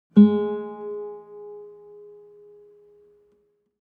mixkit-nylon-guitar-single-note-2332.wav